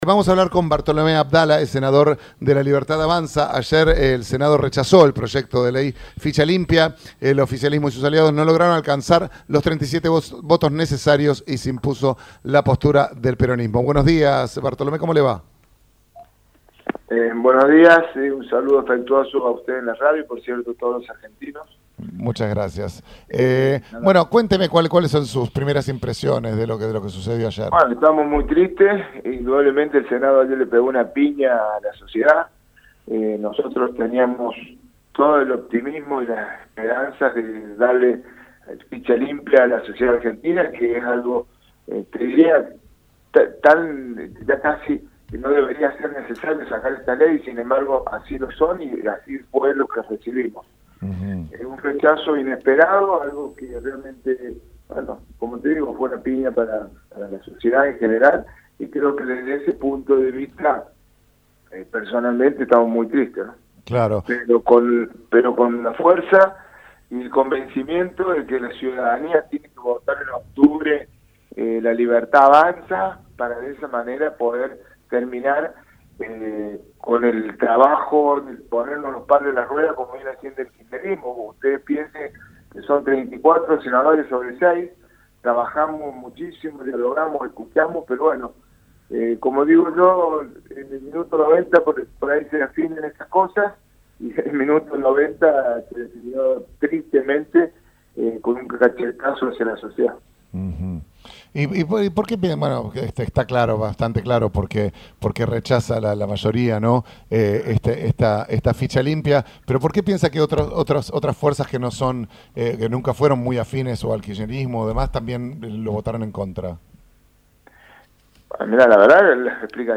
Entrevista a Bartolomé Abdala
Bartolomé Abdala, senador por La Libertad Avanza, dialogó con Ramos generales y afirmó estar "muy triste", tras considerar que "el Senado ayer le pegó una piña a la sociedad argentina".